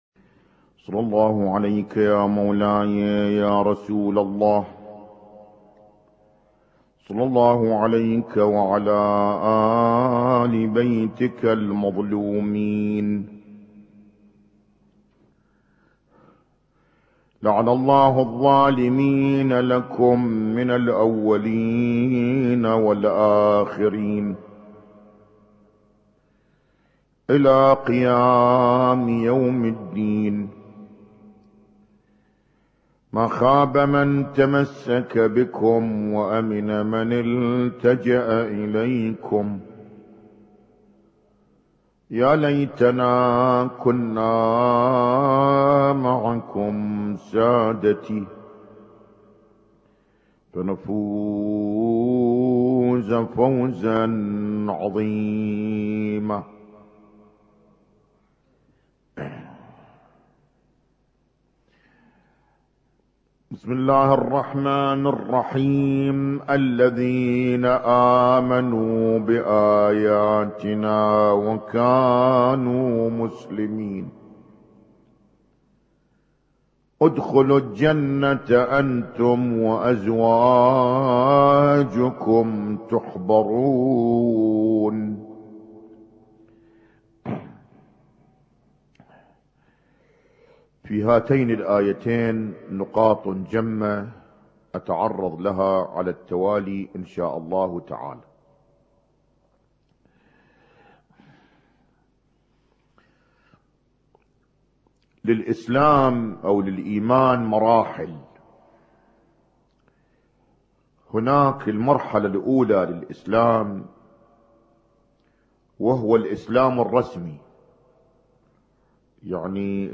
اسم التصنيف: المـكتبة الصــوتيه >> المحاضرات >> المحاضرات الاسبوعية ما قبل 1432